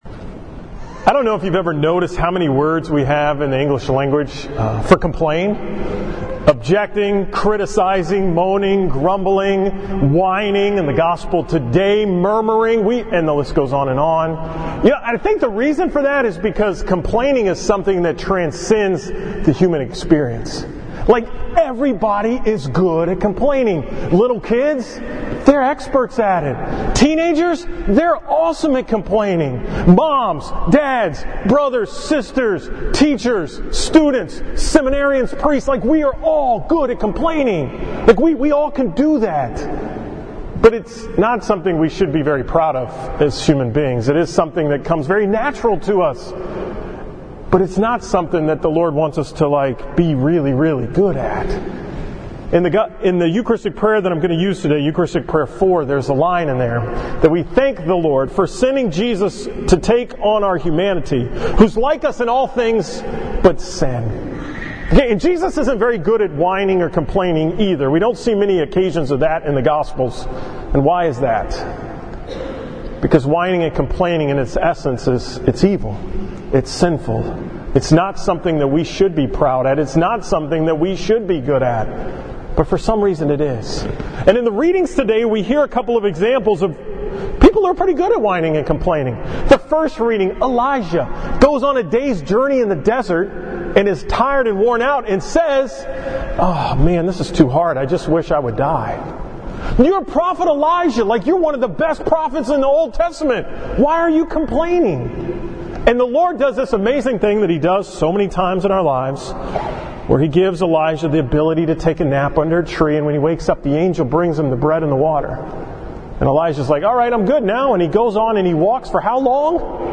From the 9 am Mass at St. Martha's on Sunday, August 12, 2018.